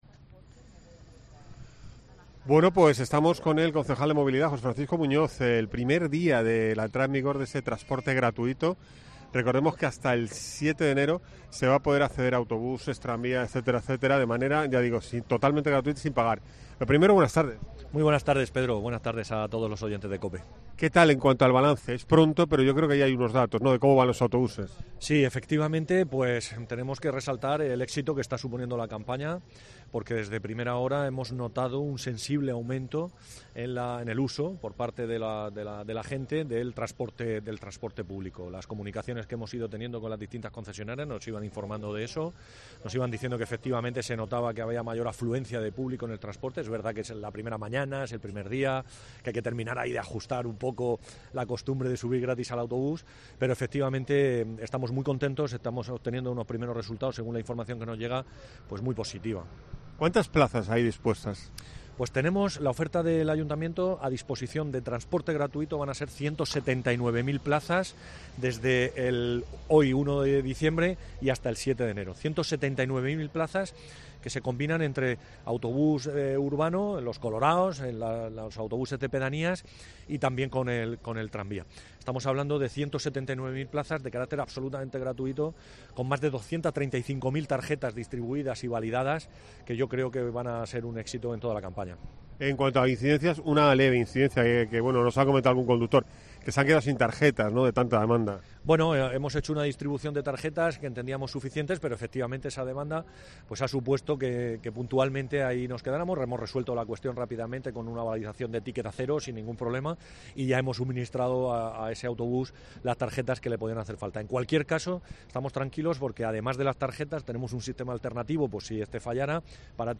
José Francisco Muñoz, concejal de Movilidad